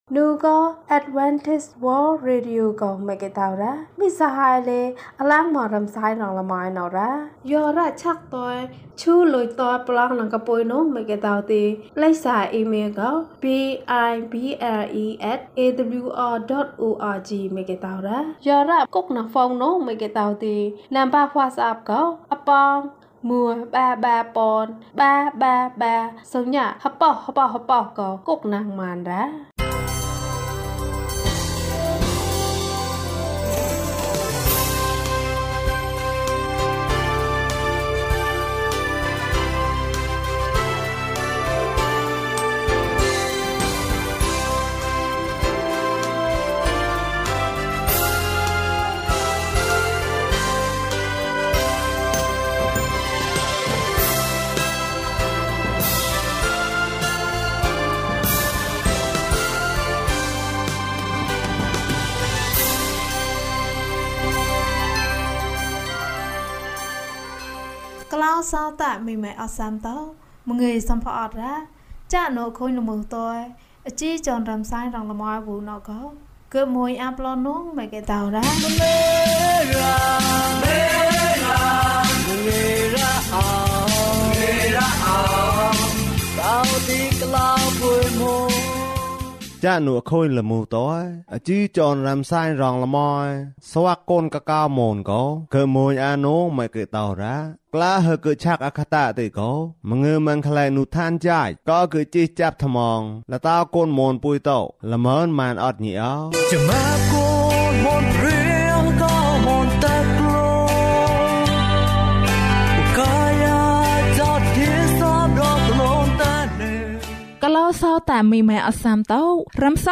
ခရစ်တော်ထံသို့ ခြေလှမ်း။၃၆ ကျန်းမာခြင်းအကြောင်းအရာ။ ဓမ္မသီချင်း။ တရားဒေသနာ။